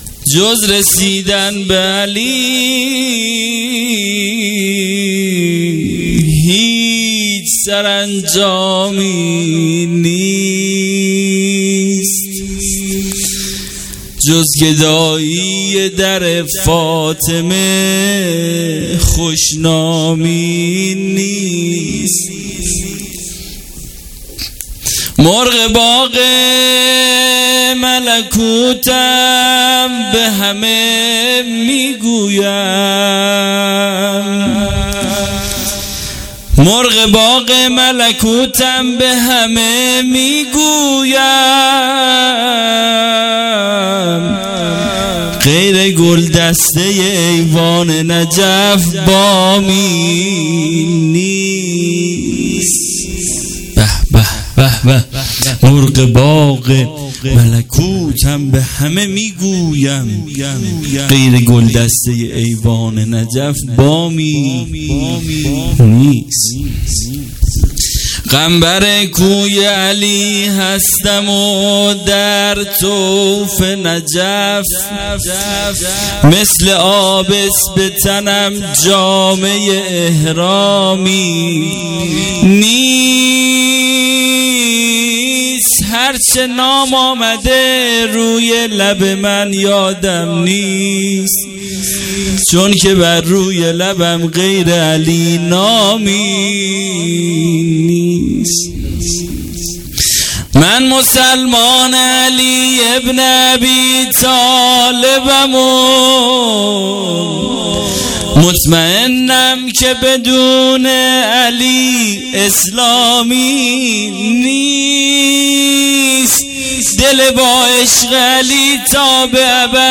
قم جشن میلاد امام علی(ع) 99 جشن میلاد امام علی(ع